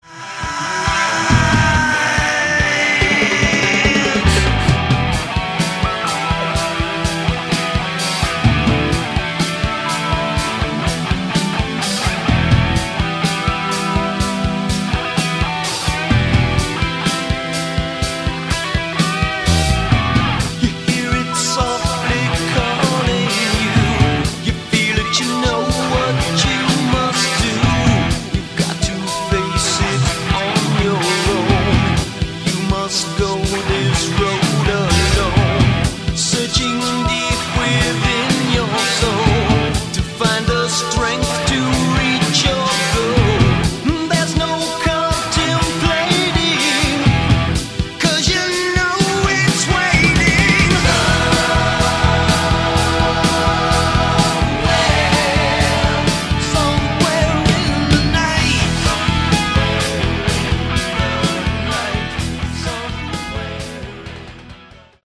Category: Hard Rock
drums, percussion
lead guitar
bass, backing vocals
lead vocals, rthythm guitar
keyboards, backing vocals